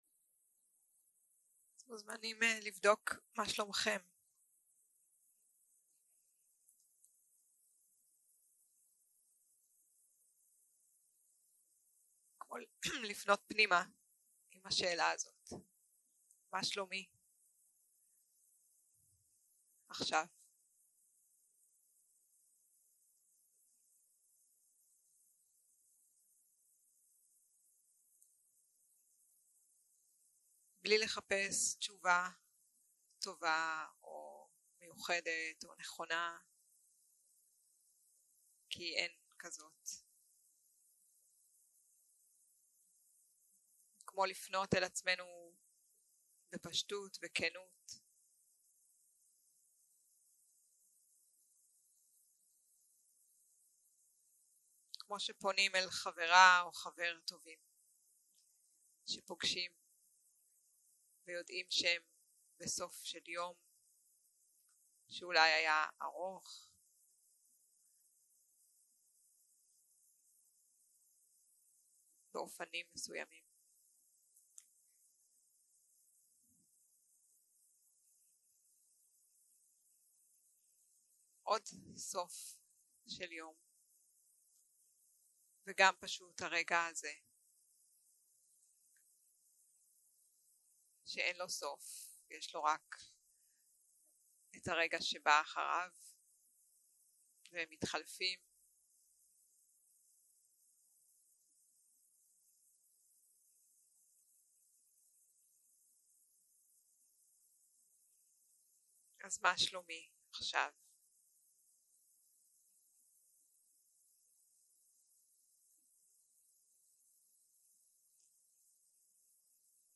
יום 6 - הקלטה 18 - לילה - מדיטציה מונחית - מטא